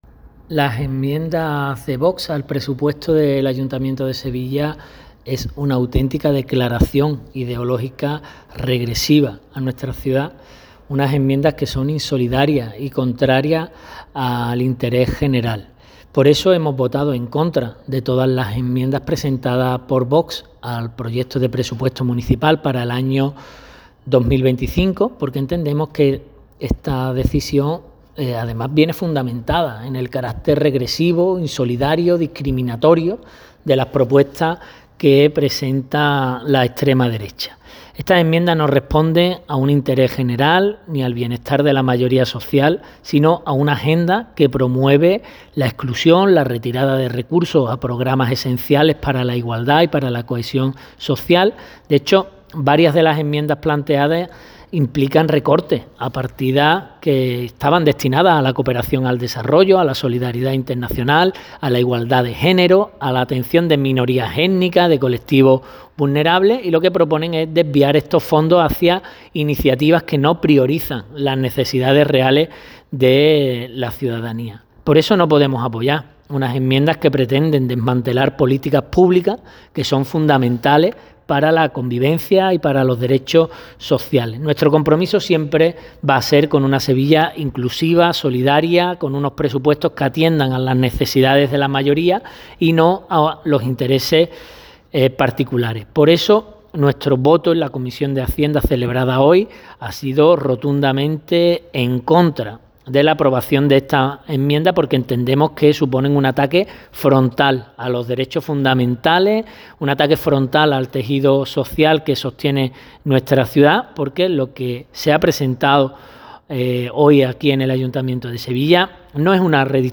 El edil ha afirmado en el desarrollo de la Comisión de Hacienda que “nuestro voto es rotundamente en contra de la aprobación de estas enmiendas por ser un ataque frontal a los derechos fundamentales y al tejido social que sostiene a nuestra ciudad. Lo que hoy se presenta aquí no es una redistribución presupuestaria, sino un manifiesto ideológico que perjudica deliberadamente a la mayoría social, a los colectivos migrantes, al feminismo, a la comunidad LGTBI, al movimiento memorialista y a las políticas ambientales”.
En un segundo turno, el edil ha reiterado su rechazo frontal a estas enmiendas y exigido al equipo de gobierno que recapacite.